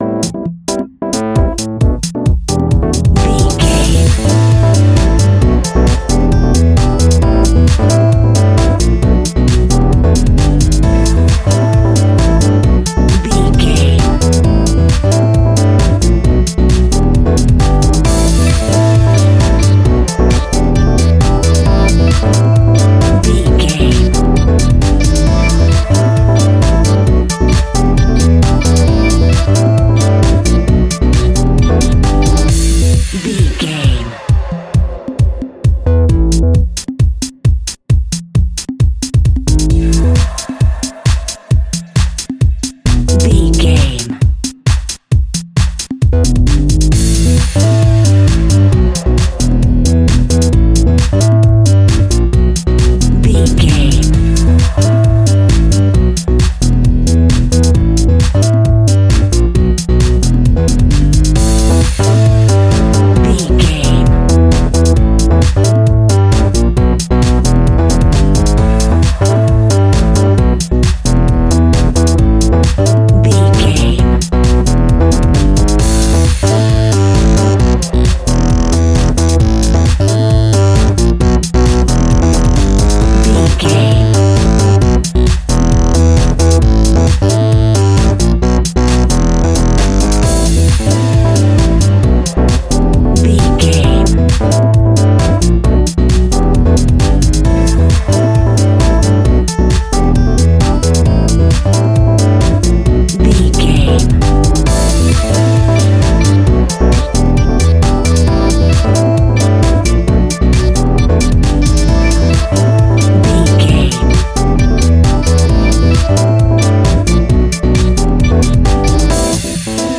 Aeolian/Minor
aggressive
powerful
uplifting
driving
energetic
synthesiser
drum machine
electro house
funky house instrumentals
synth bass
guitar